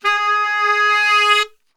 G#2 SAXSWL.wav